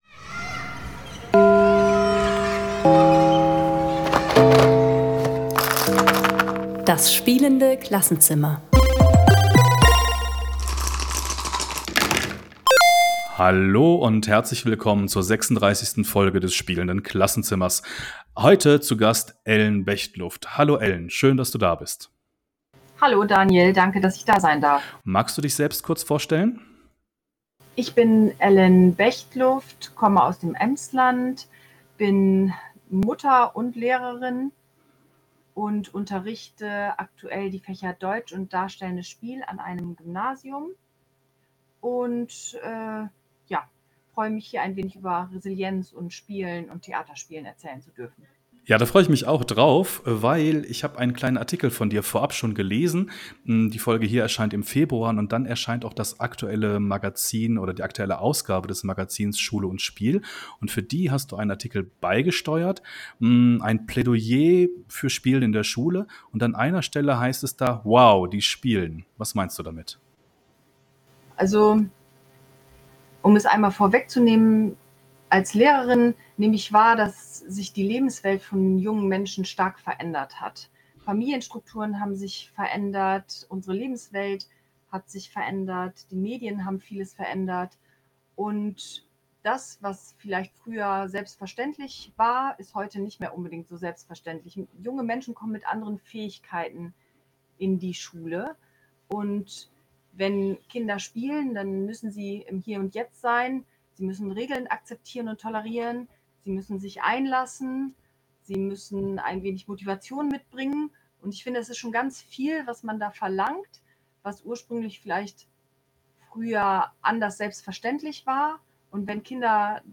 Resilienz - auch mal scheitern dürfen (Interview